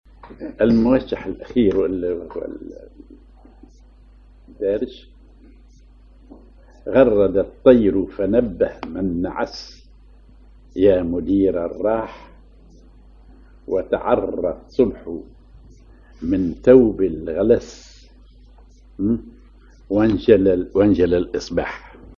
Maqam ar نكريز
Rhythm ar سماعي ثقيل
genre موشح